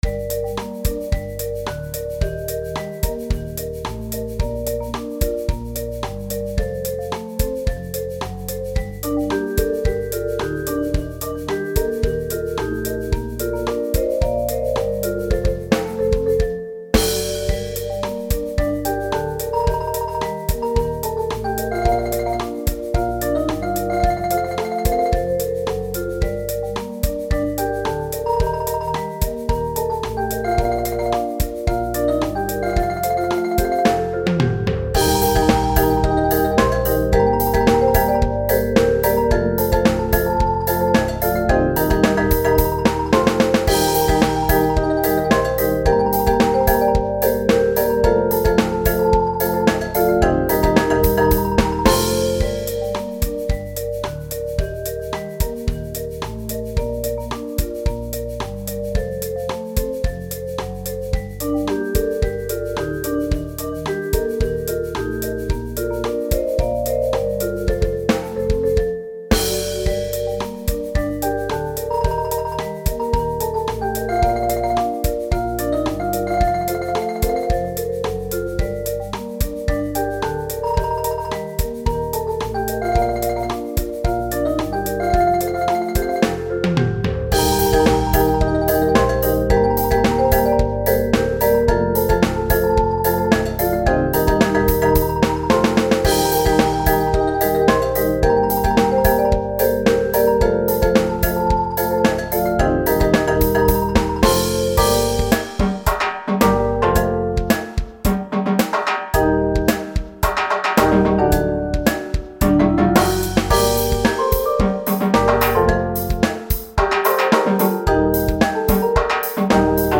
Mallet-Steelband Muziek